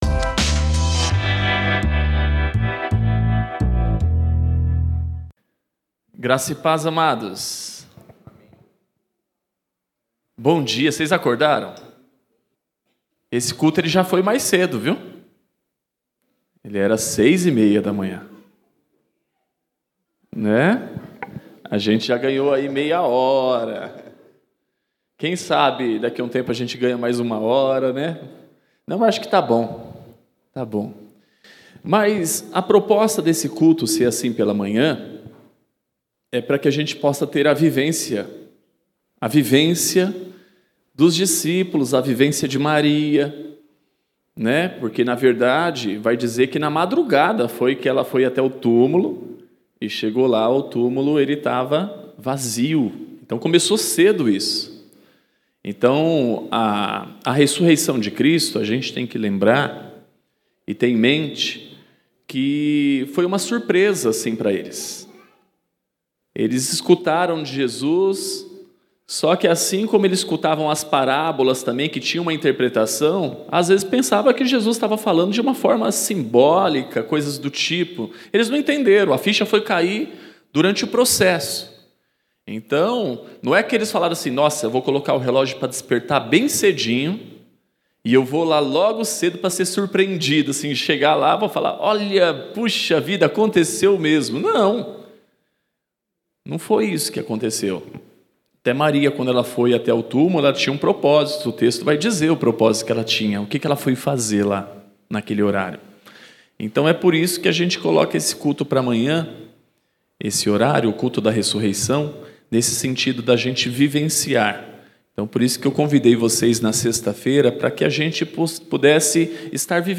Mensagem especial de Páscoa, realizado no Domingo.